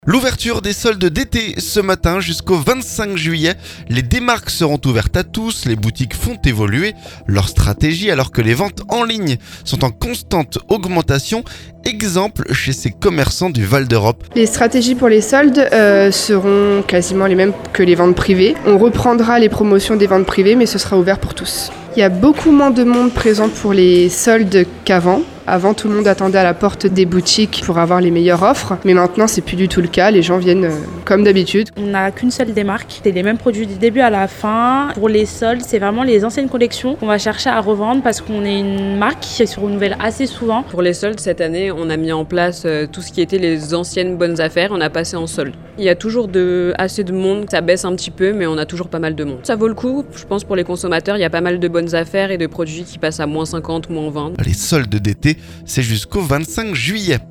SOLDES - Reportage au Val d'Europe au premier jour des rabais
Les boutiques font évoluer leur stratégie alors que les ventes en ligne sont en constante augmentation. Exemple chez ces commerçants du Val d'Europe.